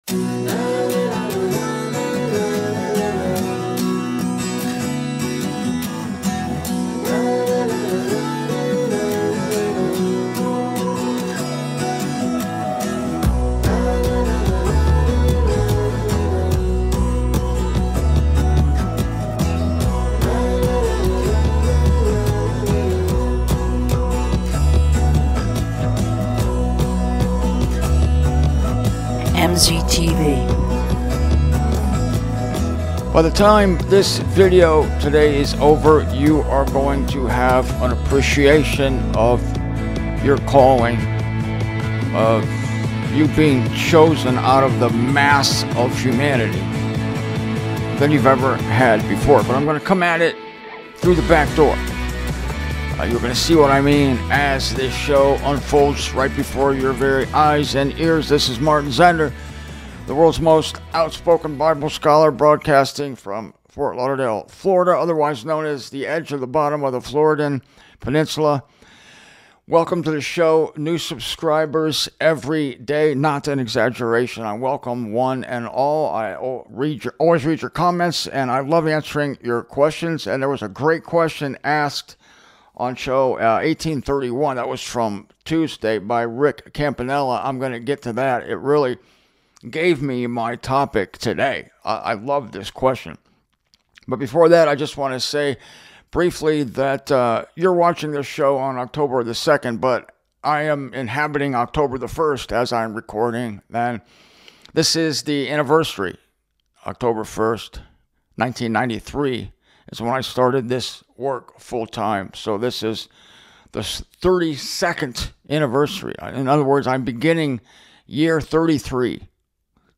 The teaching that follows is the shocking awareness that God actively hides Himself from the majority of the human race; here are three hard-hitting Scripture verses proving it.